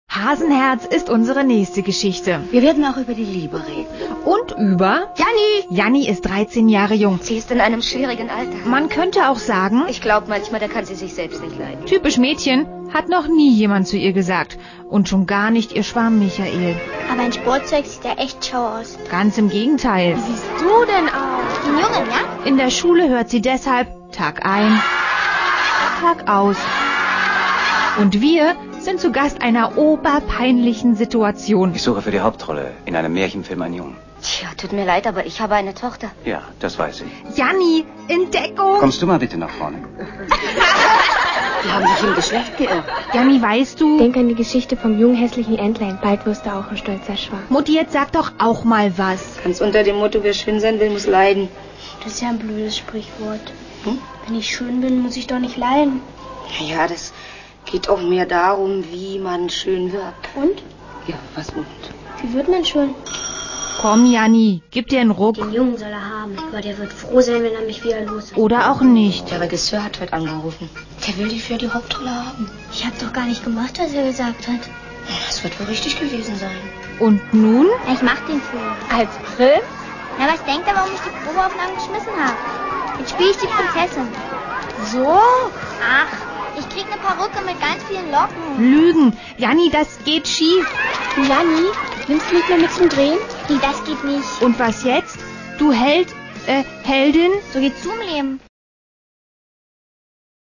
Audio-Trailer